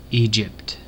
Pronunciation: / ˈ iː dʒ ɪ p t /
En-us-Egypt.ogg.mp3